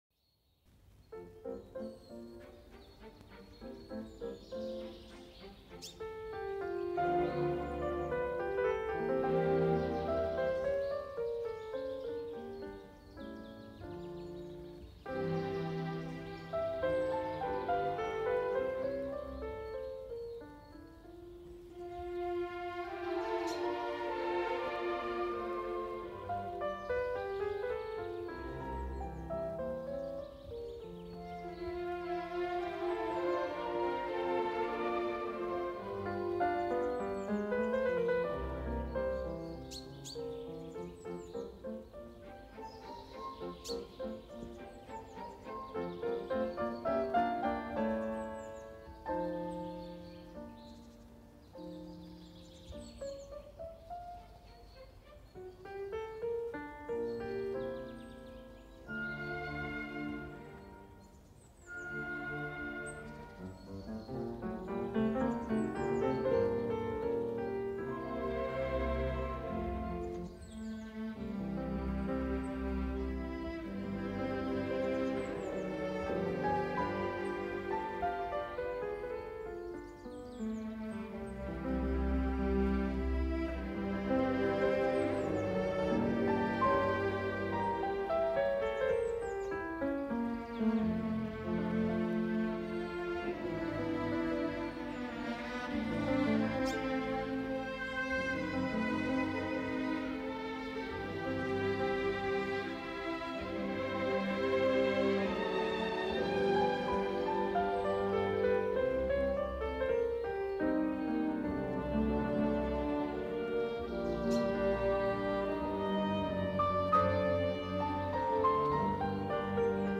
Schumann – Concierto para piano Op. 54 | Sesión de estudio